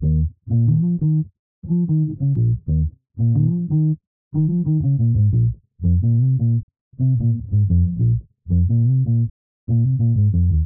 dhh2_royal_bass_loop_90_A#m
dhh2_royal_bass_loop_90_Am.wav